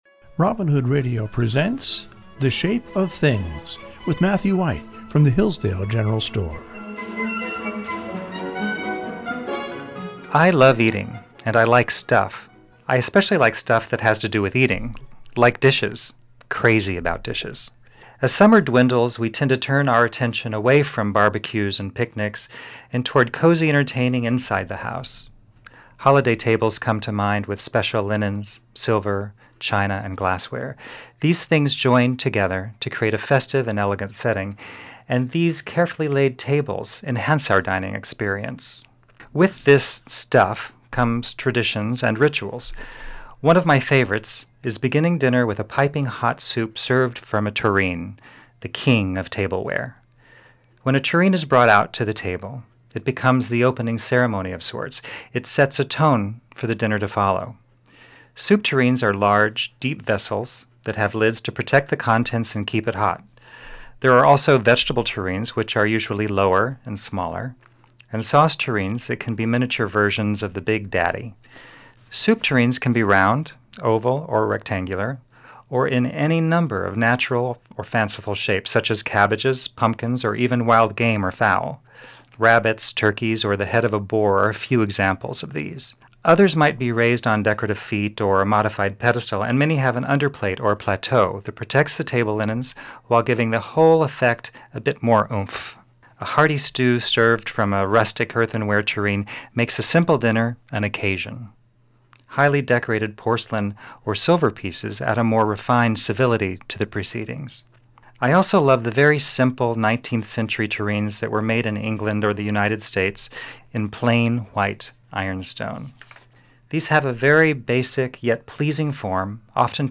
reads short stories he has written based on the region.